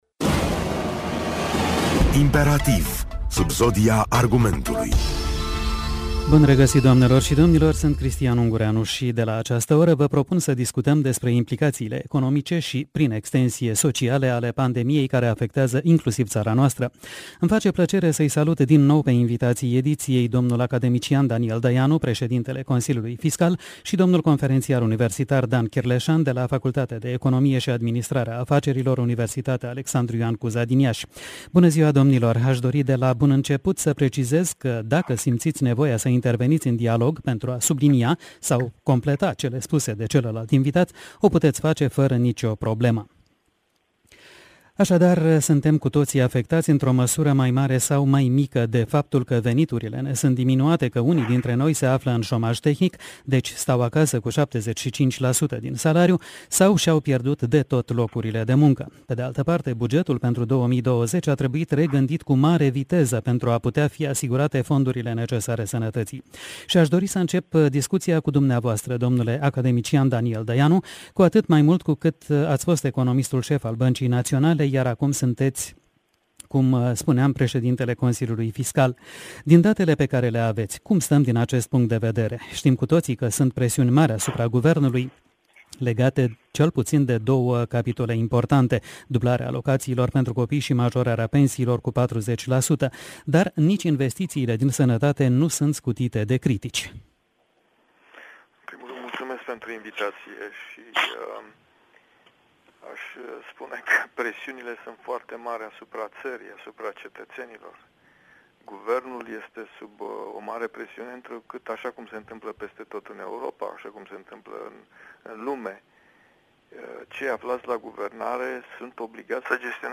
Ca în fiecare vineri, de la ora 17,15, Radio Iași vă propune emisiunea de dezbatere cu genericul IMPERATIV. Săptămîna aceasta, vom discuta despre efectele pandemiei COVID 19 asupra noastră, a tuturor, dar din perspectiva economică.